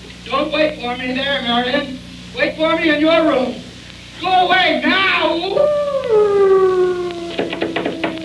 wolfhowl.wav